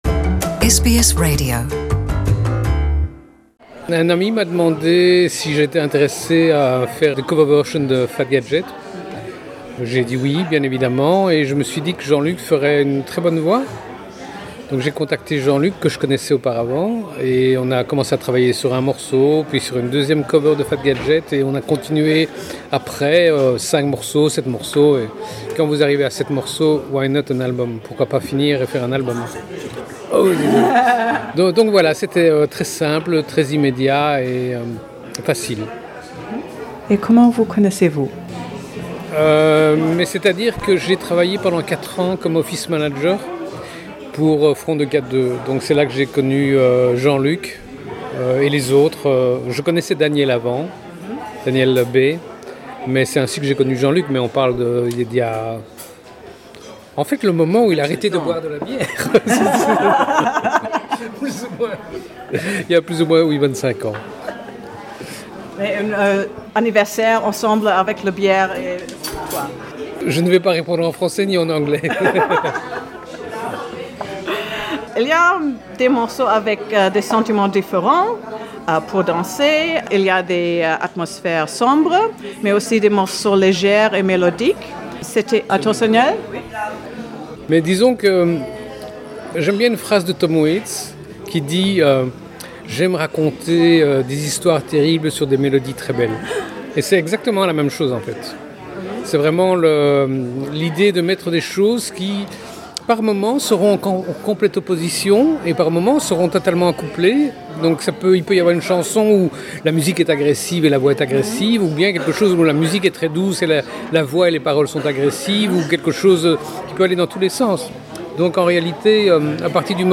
Rencontre avec deux musiciens belges